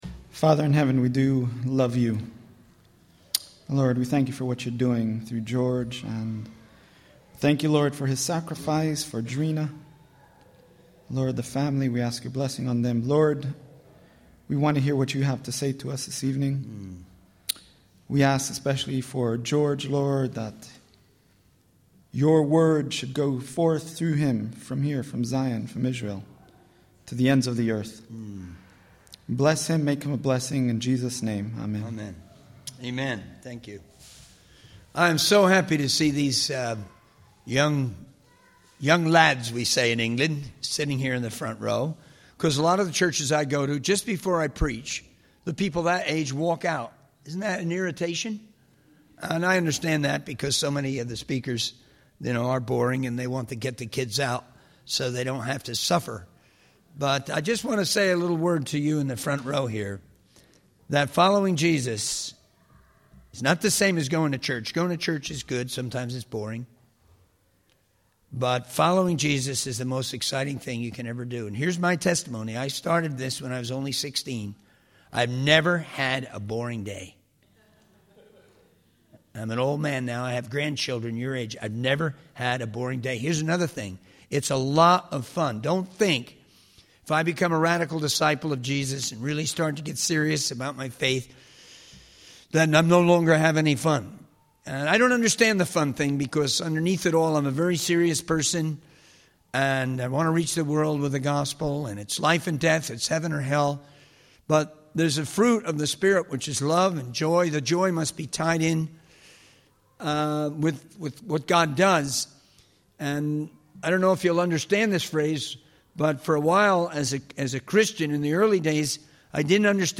In this sermon, the speaker begins by referencing Hebrews 12 and encourages the audience to strip off any weights that hinder their progress in the race of faith. He draws a parallel to the Winter Olympics and the intense training and discipline required to win.